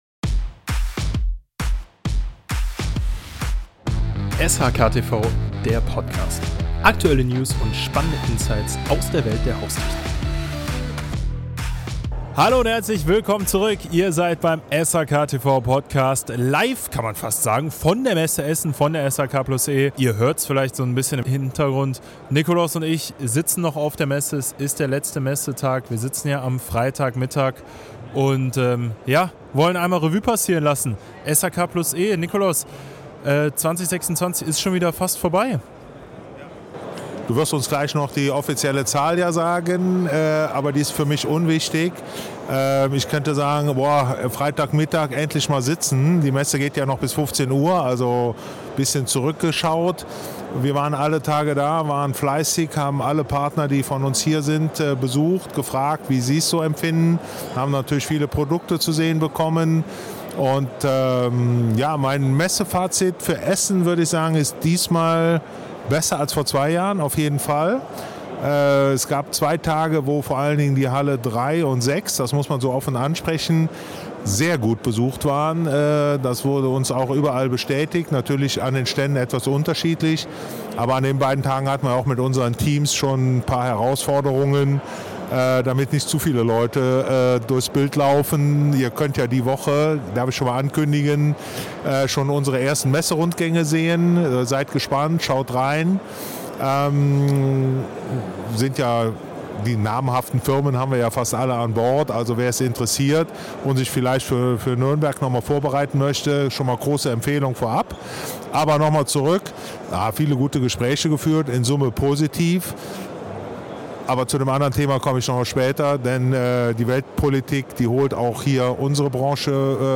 Direkt vom letzten Messetag der SHK+E 2026 in Essen nehmen wir unseren Podcast live vor Ort auf und ziehen ein Fazit der Messewoche.